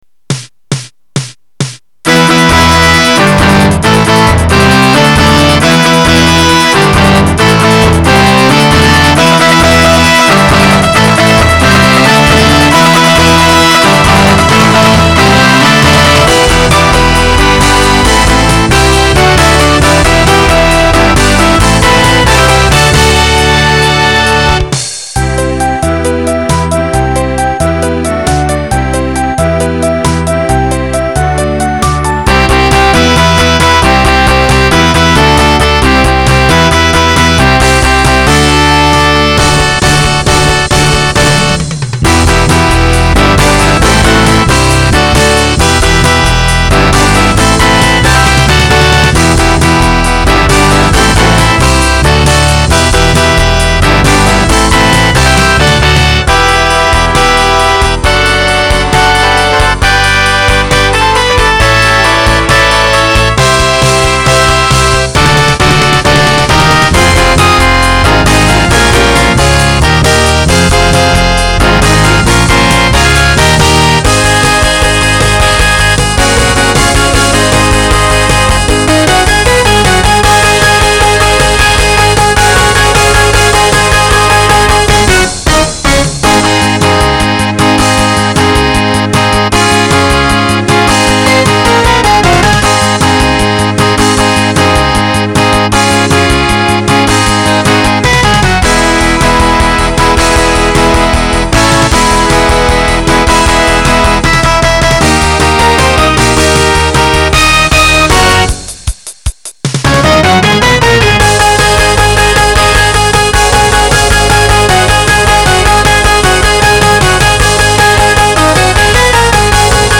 (SATB)
Voicing Mixed Instrumental combo Genre Rock